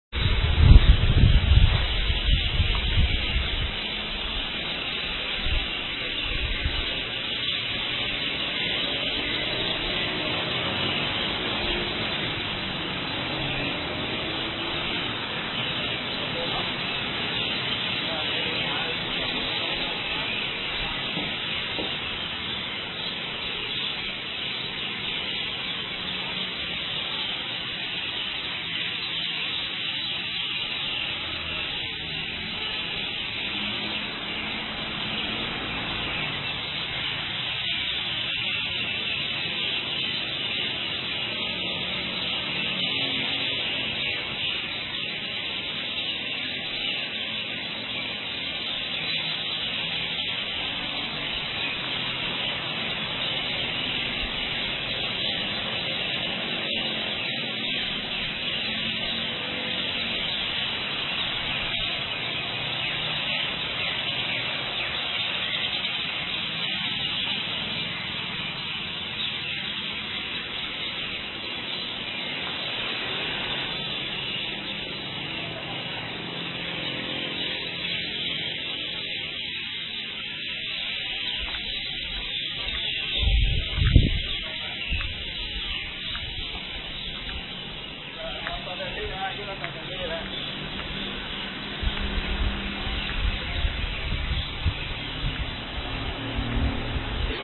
voiceofbirds.mp3